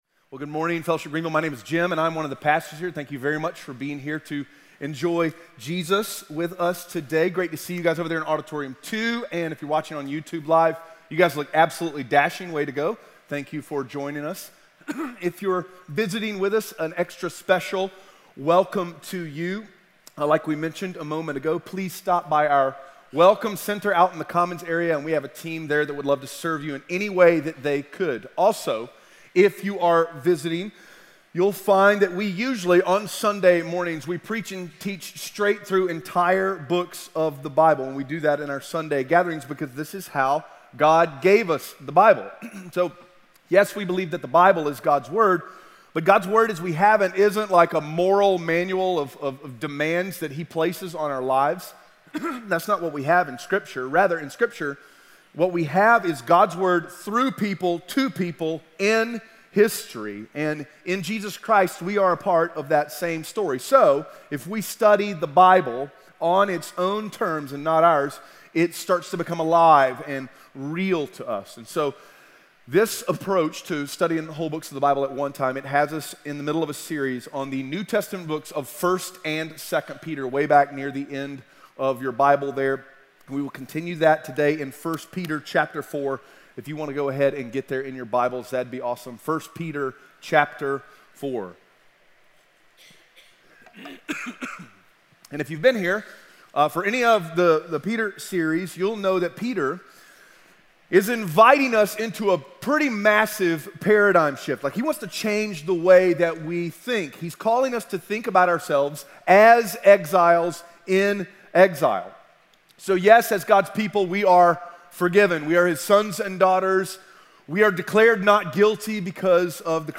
1 Peter 4:12-19 Audio Sermon Notes (PDF) Ask a Question Early Christianity wasn’t glamorous.